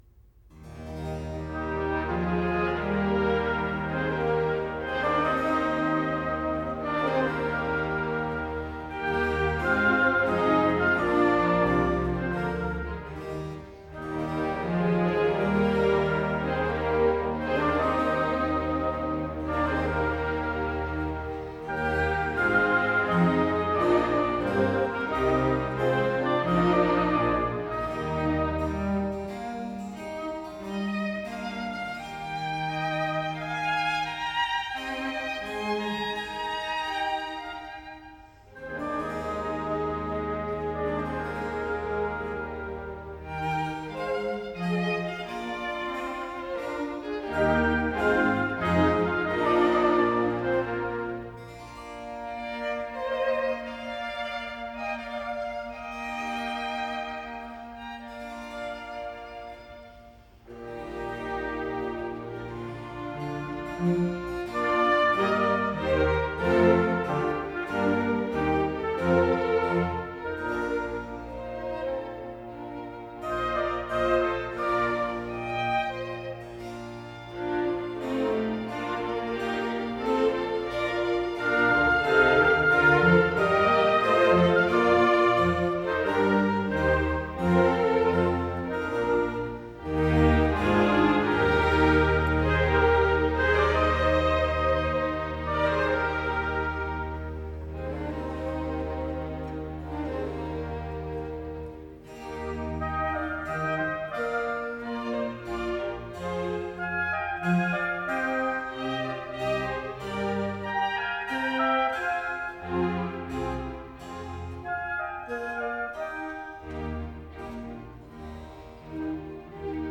G minor - Musette, larghetto